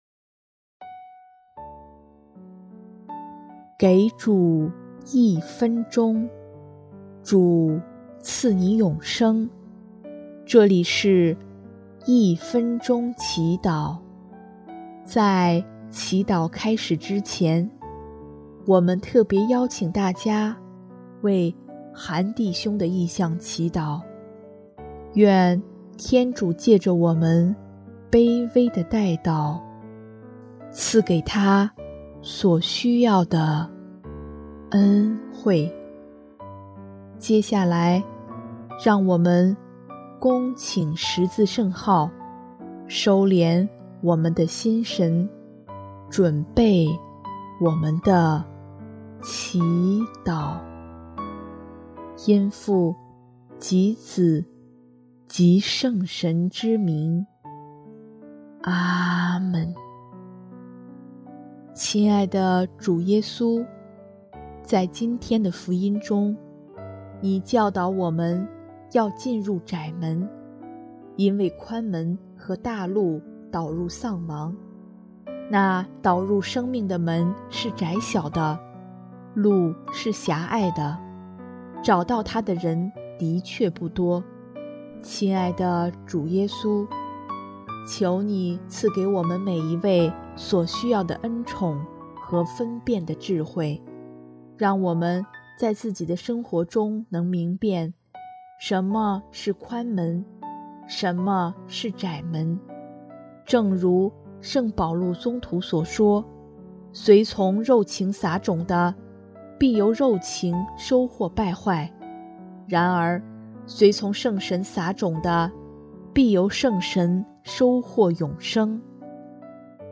【一分钟祈祷】|6月25日 通往生命的门